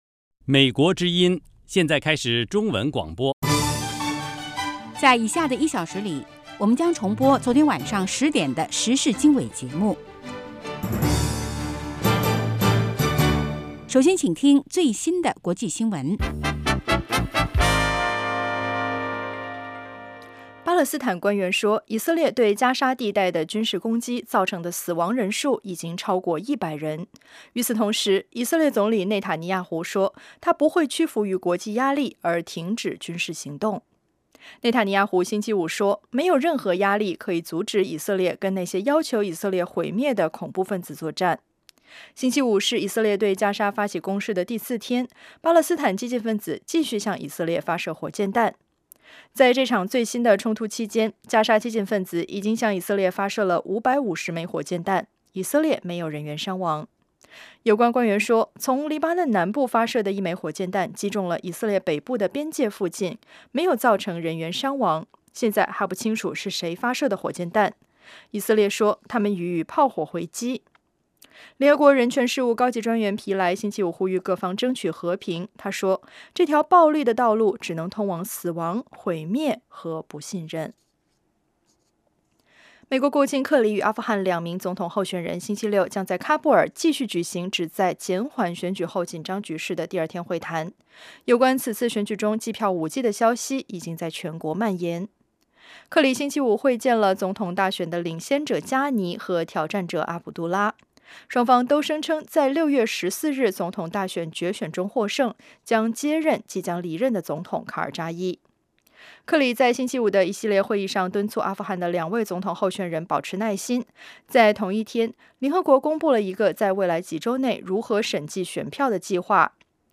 国际新闻 时事经纬(重播) 北京时间: 上午6点 格林威治标准时间: 2200 节目长度 : 60 收听: mp3